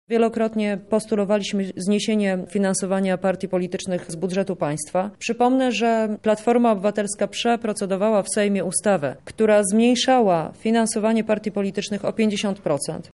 – wyjaśnia Joanna Mucha z lubelskiej PO.